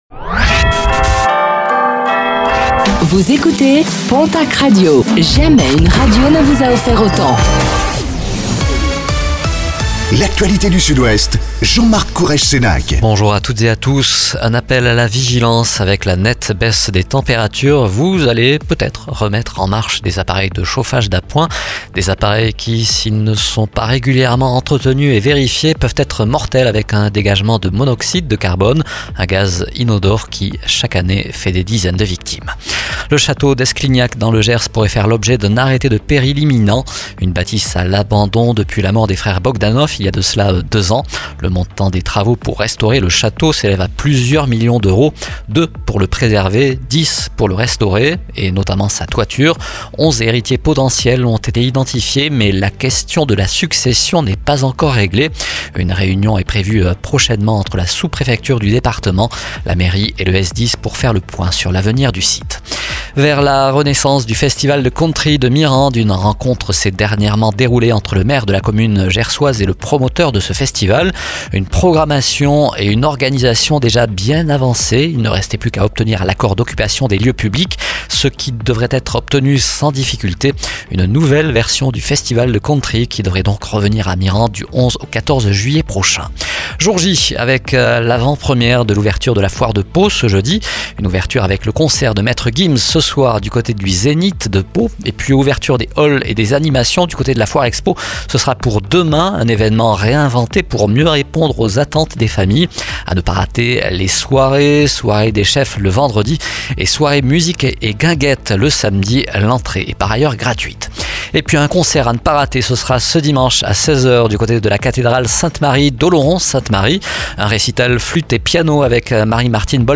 Infos | Jeudi 12 septembre 2024